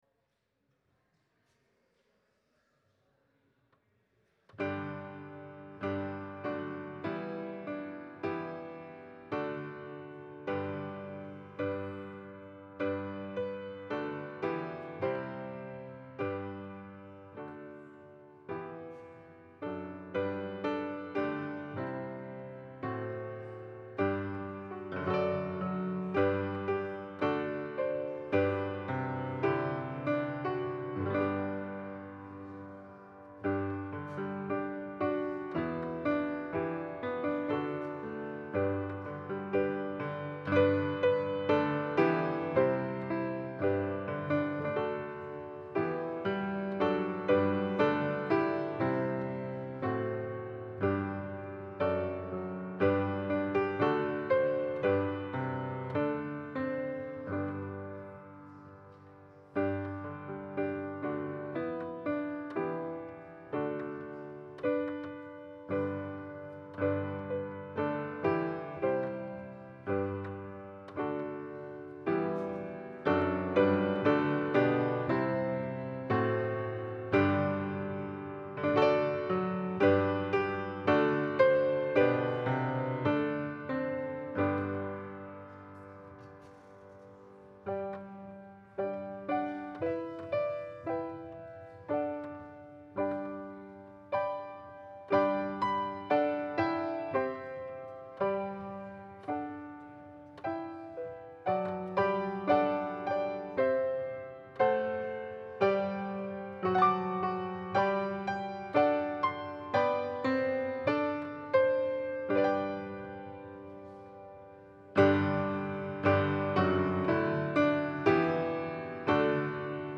Passage: Ephesians 4 Service Type: Sunday Service